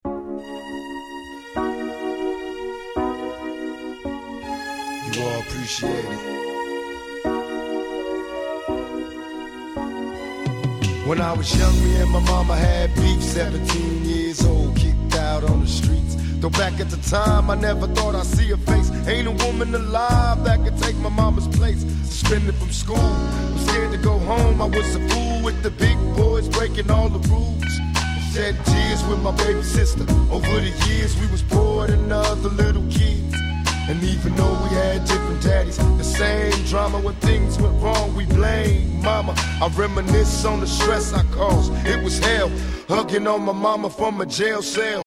※試聴ファイルは別の盤から録音してあります。